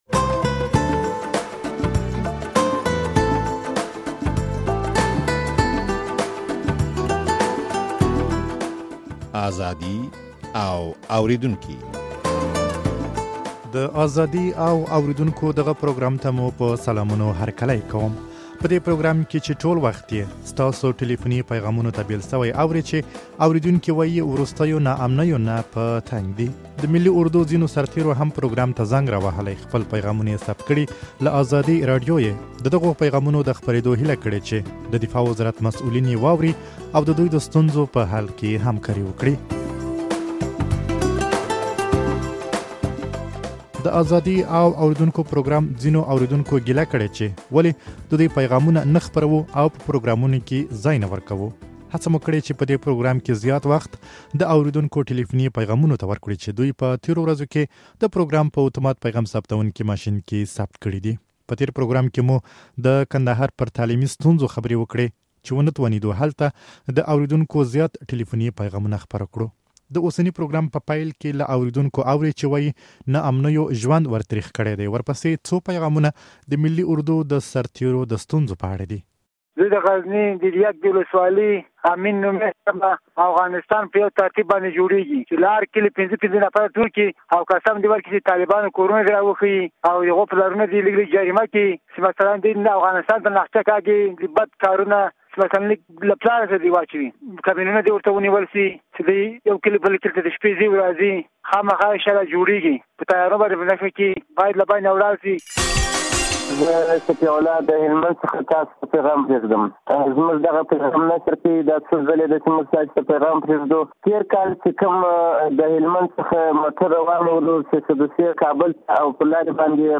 د ملي اردو زياتو سرتېرو هم پروګرام ته زنګ راوهلى، خپل پيغامونه يې ثبت کړي، له ازادۍ راډيو يې د دغو پيغامونو د خپرېدو هيله کړې چې د دفاع وزارت مسوولين يې واوري